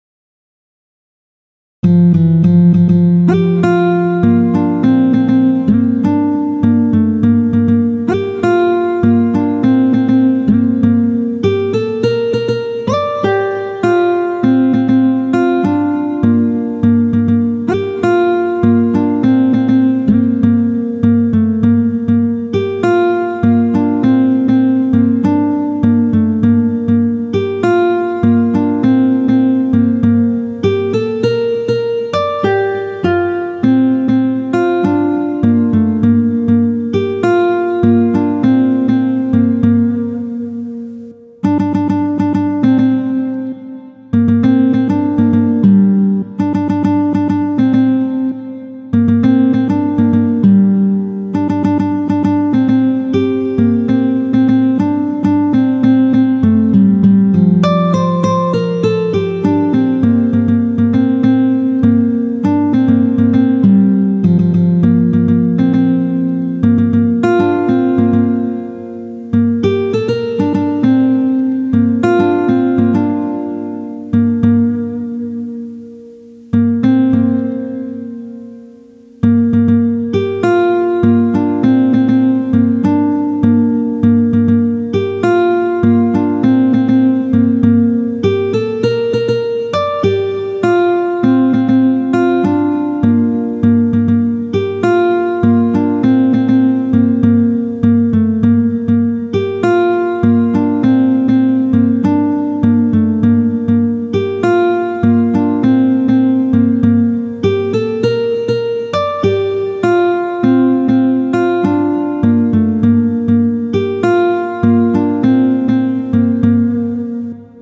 谱内音轨：电吉他